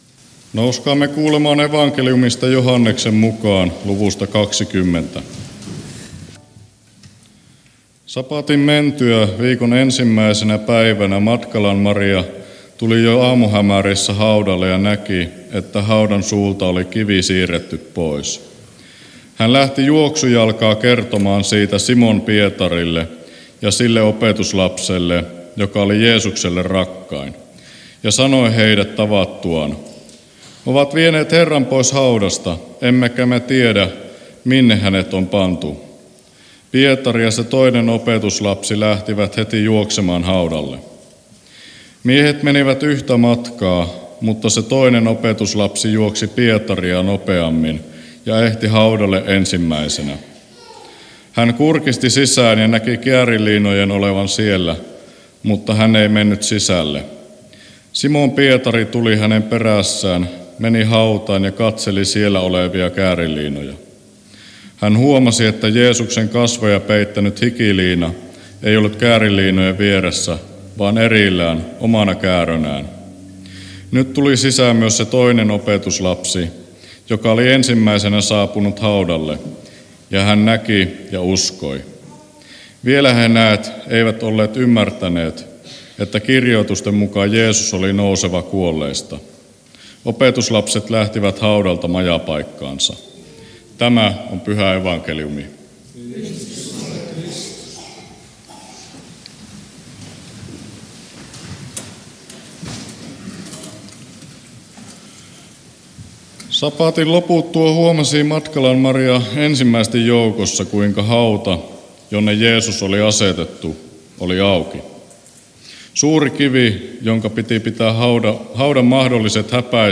Halsua